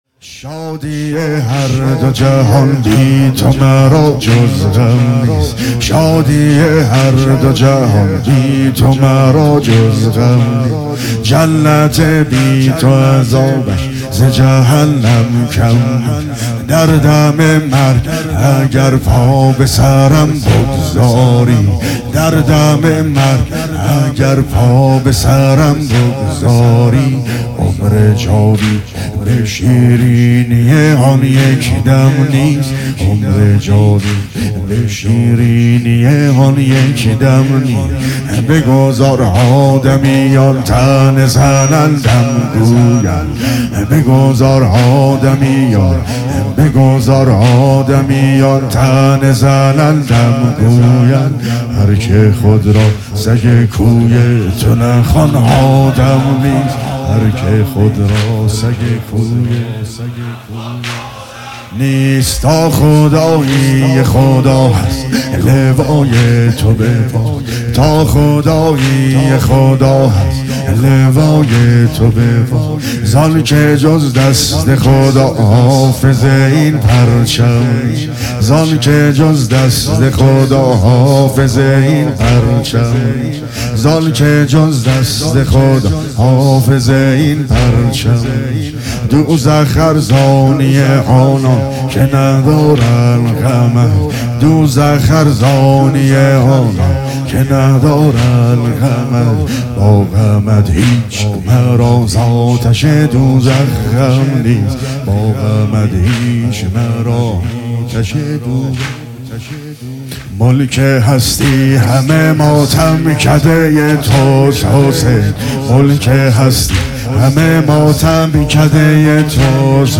مداحی جدید